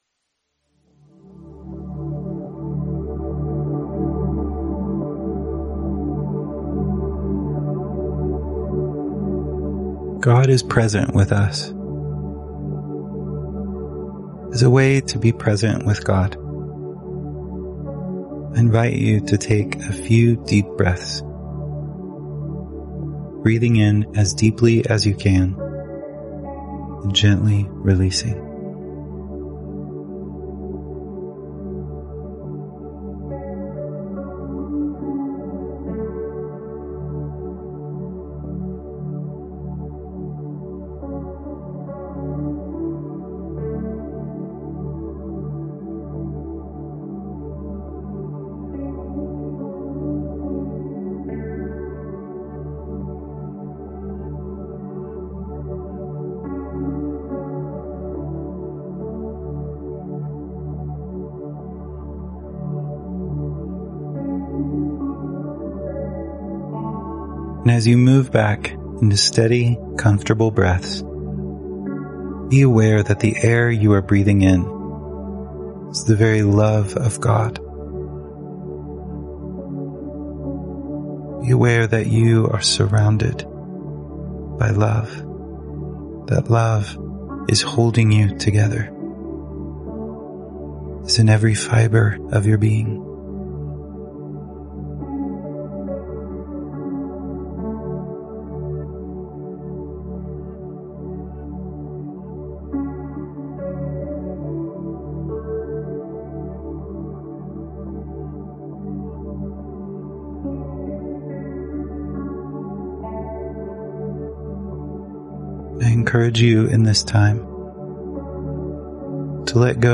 Click the audio to experience guided prayer through today's passage — then, after you're finished, take a moment with the daily breath prayer.